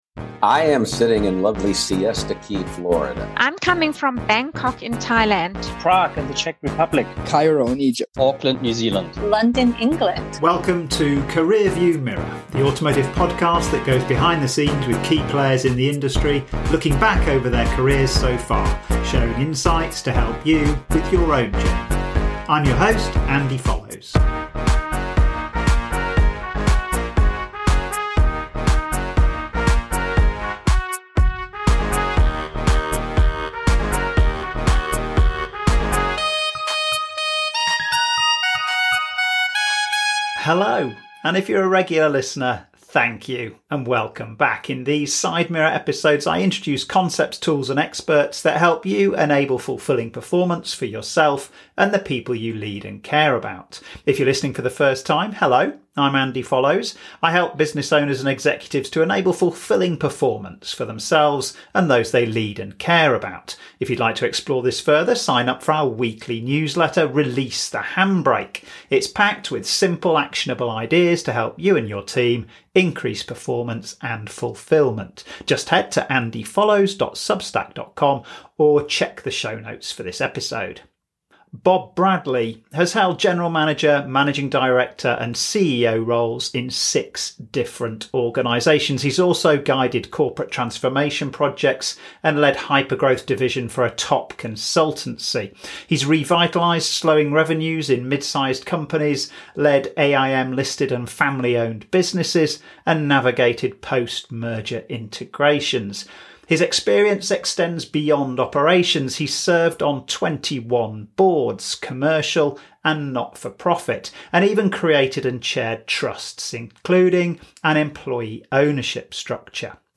Career View Mirror – An Audio Interview